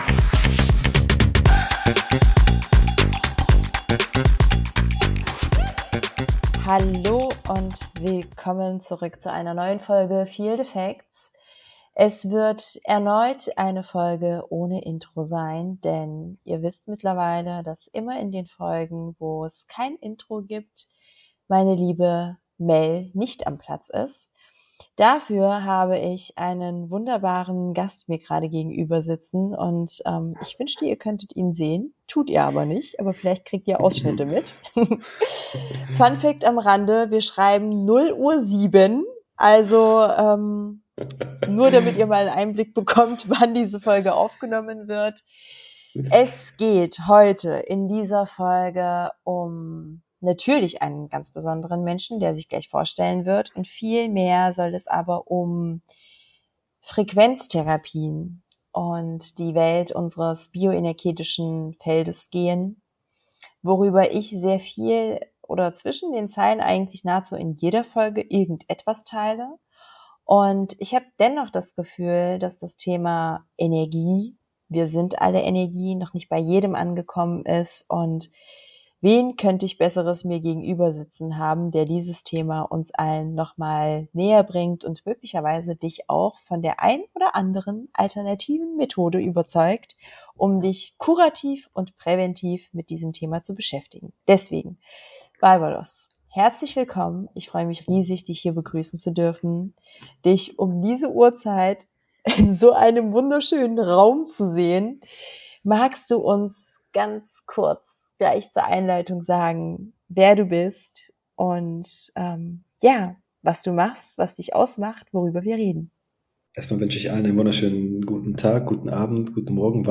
Über Frequenzen, Mikrostrom, Magnetfelder und vielen persönlichen Erfahrungen wird ungeskriptet gesprochen...
Lausche in unser Wohnzimmertalk und öffne dich anderen Sphären für dein verdientes besseres energetisches Feld.